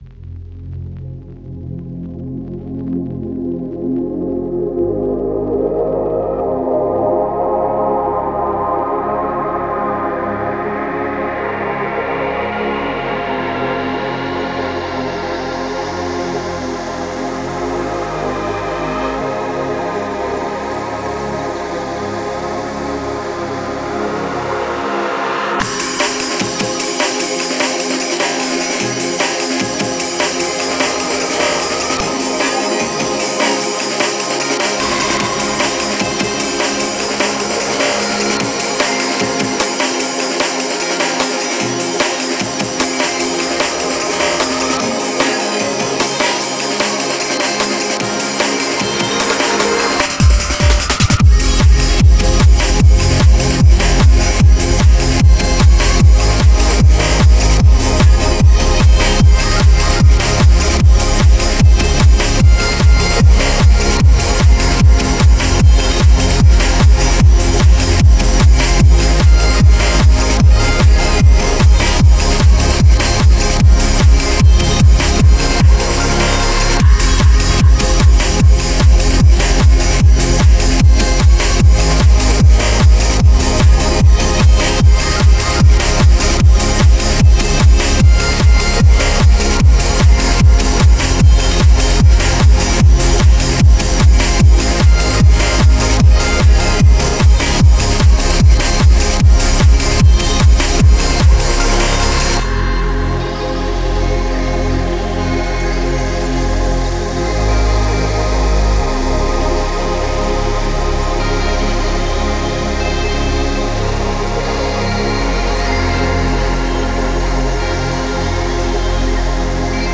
a track inspired by the early skrillex sound
its kinda.. techno? house? idek what genre to call it tbh..